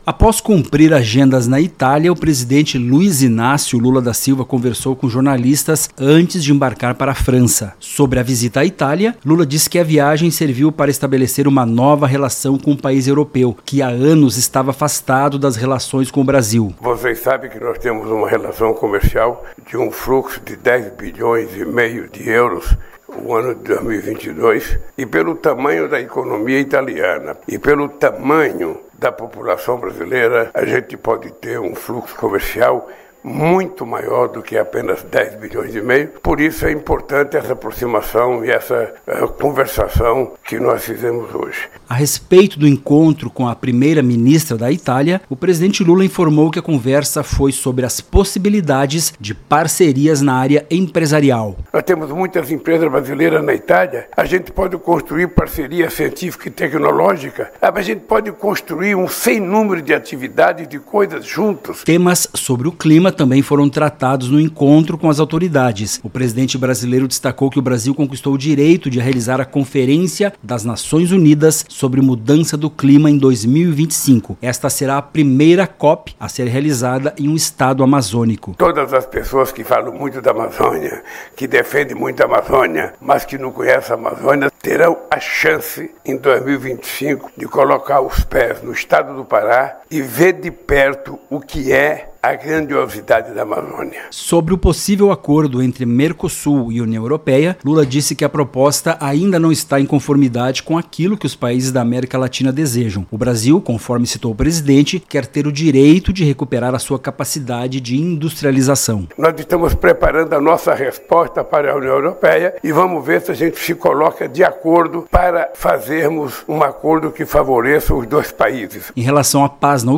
Boletins de Rádio